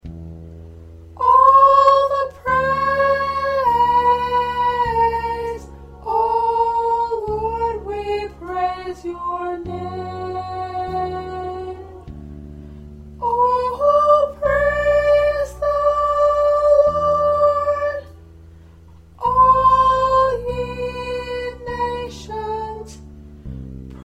Soprano preview